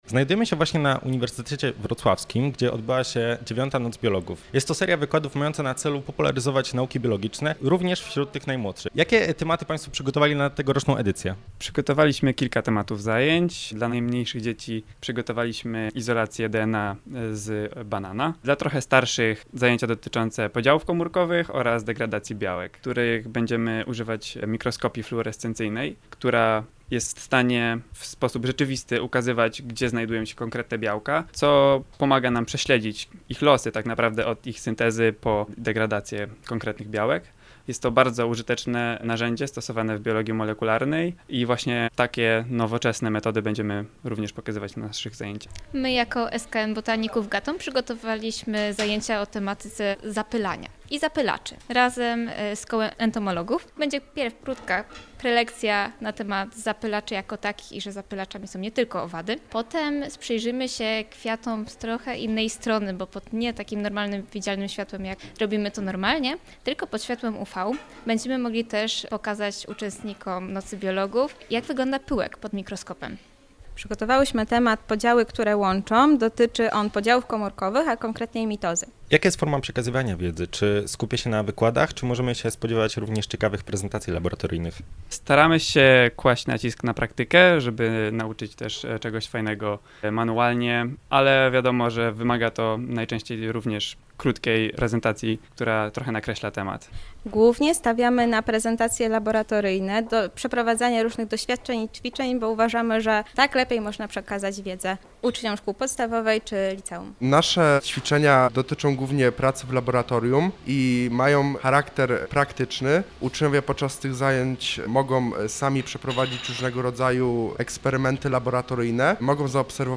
DNA z banana – relacja z Nocy Biologów 2020